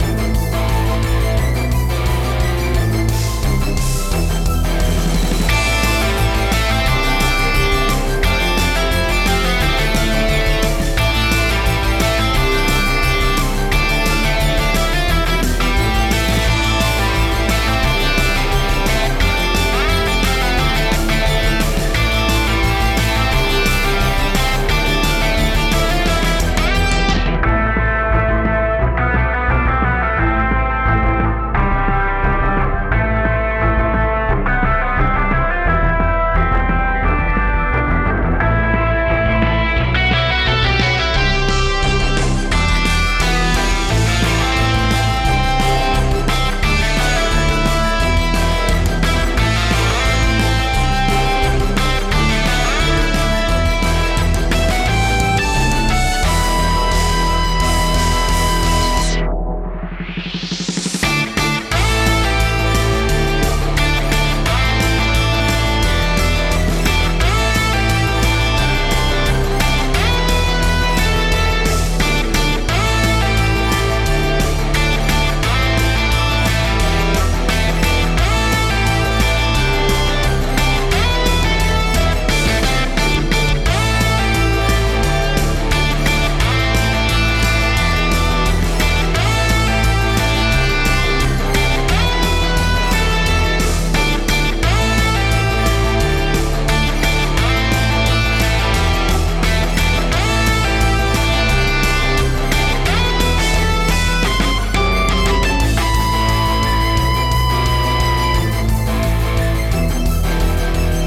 hot_roadway_climax_remake_bpm175.mp3